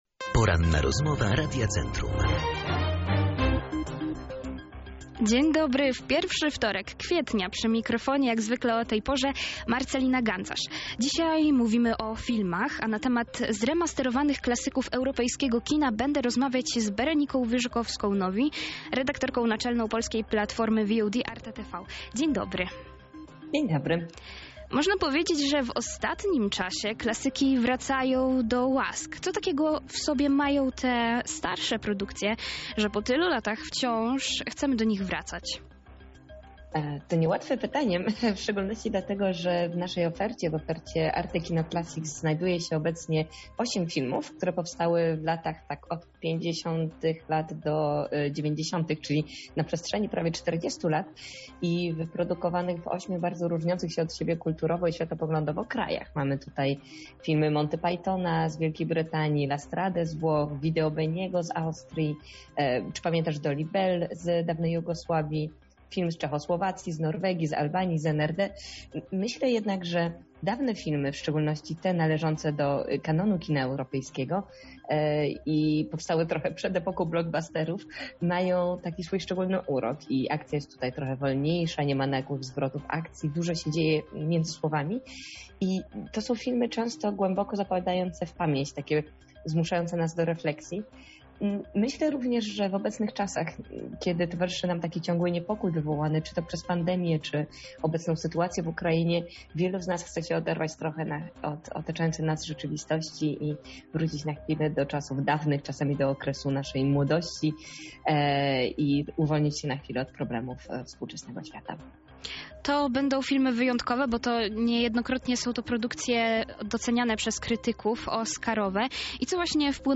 zremasterowane klasyki cała rozmowa